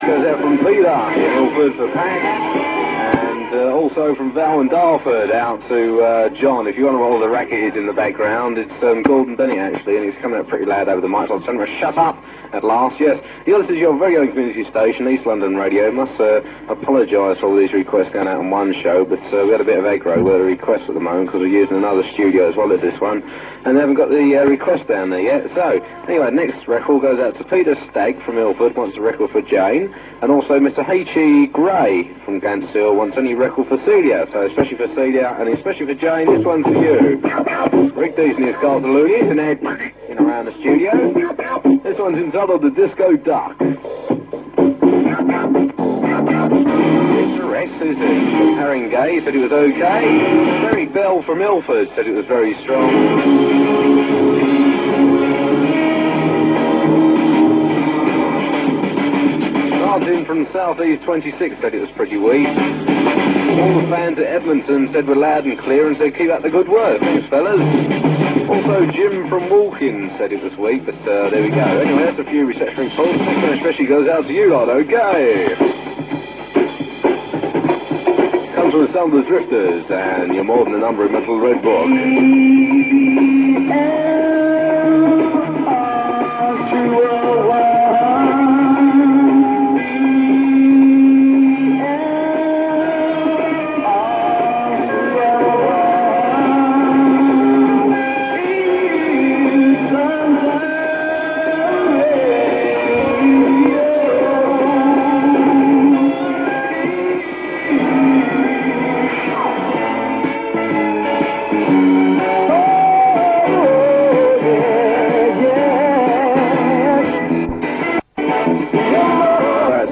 EAST LONDON RADIO 201 Metres Medium Wave
The signal was always strong and the station built up a large following due to the emphasis on the community programming.